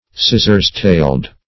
Search Result for " scissors-tailed" : The Collaborative International Dictionary of English v.0.48: Scissors-tailed \Scis"sors-tailed`\, a. (Zool.)